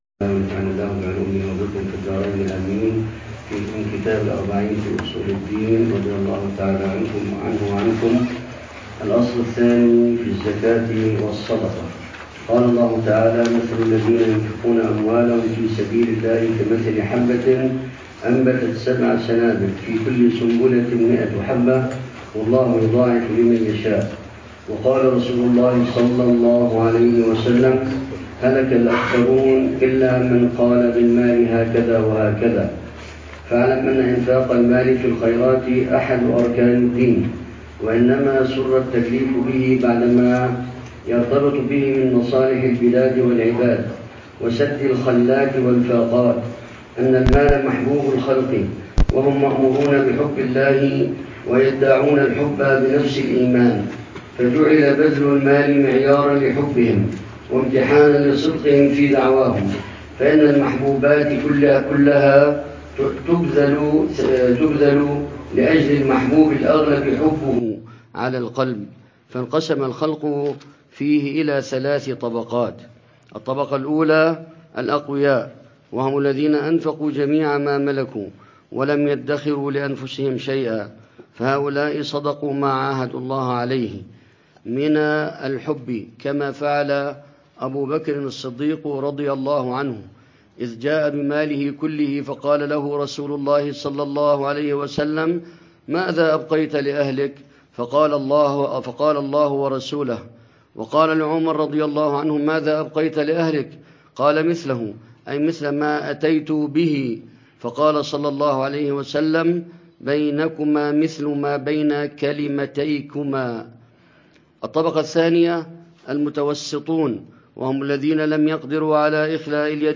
الدرس السادس للعلامة الحبيب عمر بن محمد بن حفيظ في شرح كتاب: الأربعين في أصول الدين، للإمام الغزالي .